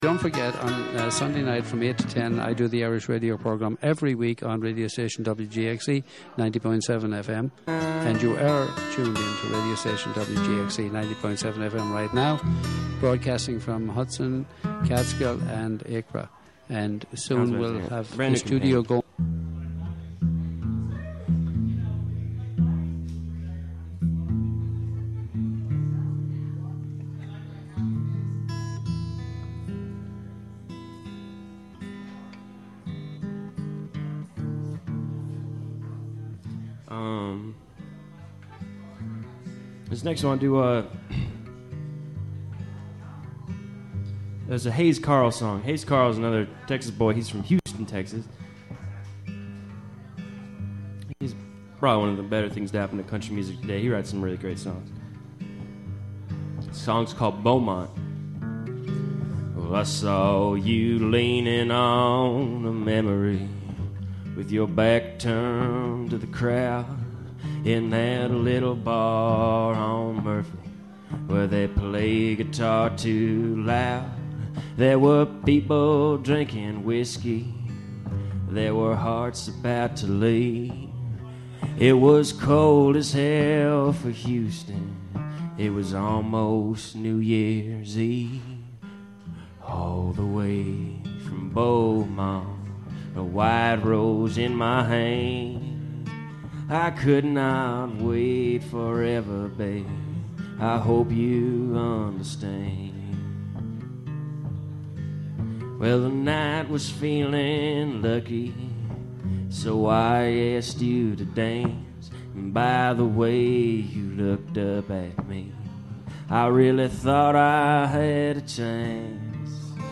Live from The Red Dot Open Mic